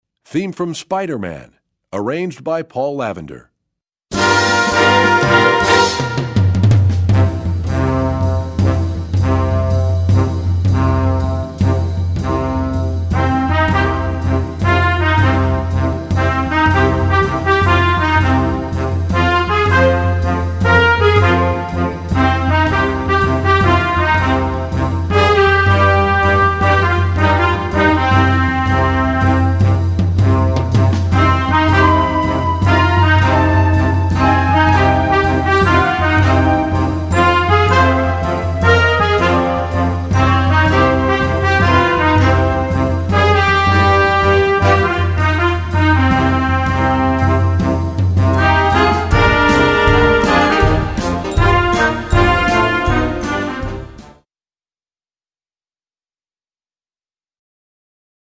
Gattung: Filmmusik
23 x 30,5 cm Besetzung: Blasorchester Tonprobe